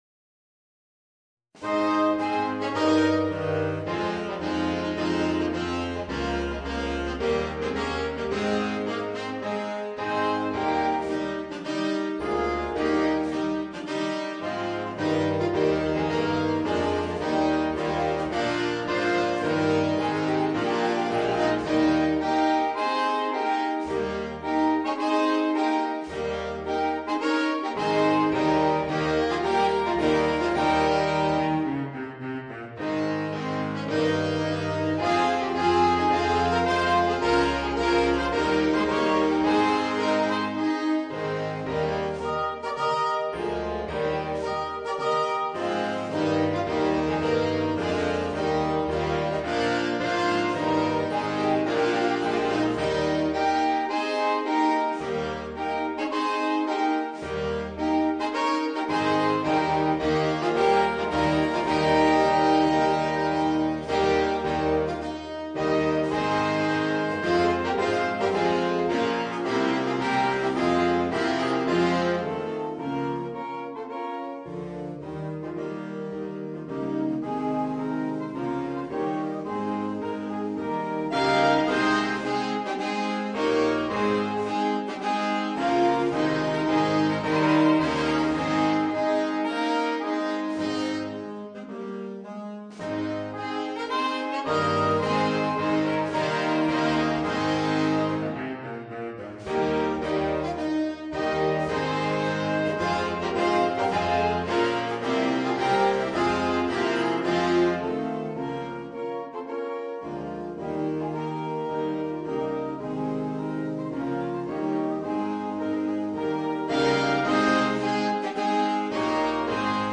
Voicing: 8 Saxophones